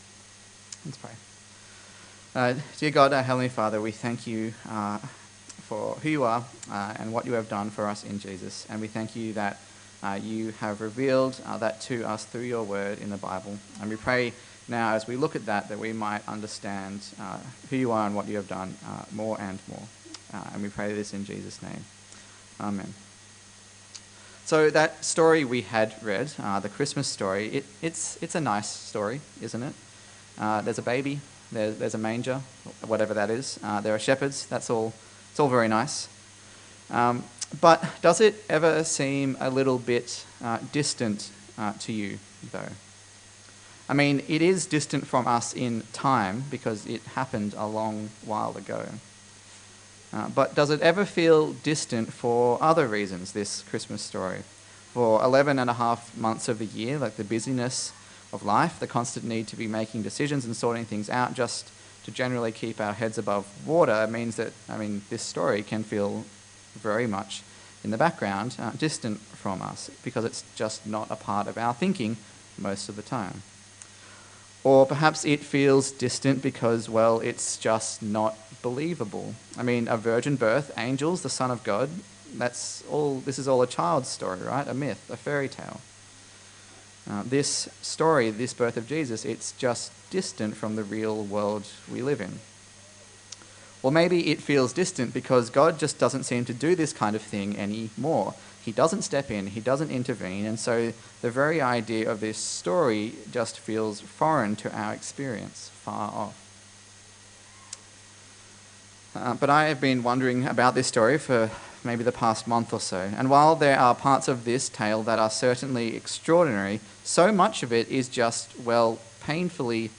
Christmas 2021 Passage: Luke 2:1-21 Service Type: Christmas Day